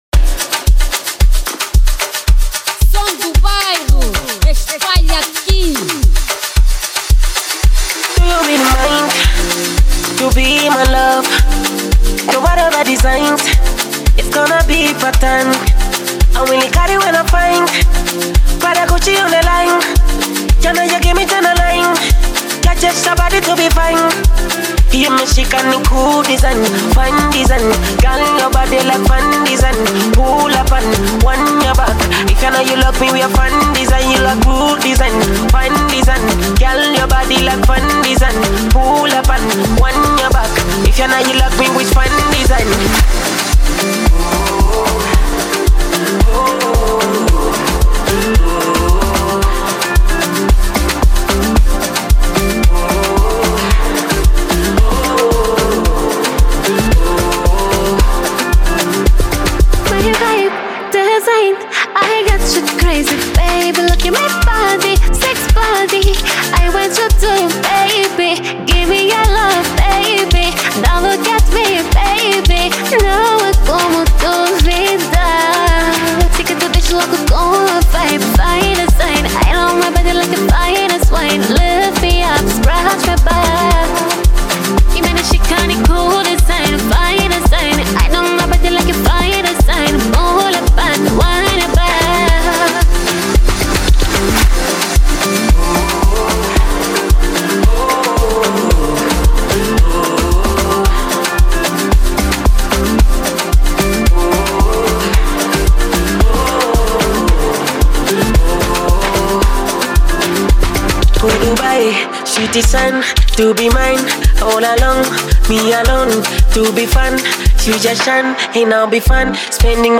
| Afro house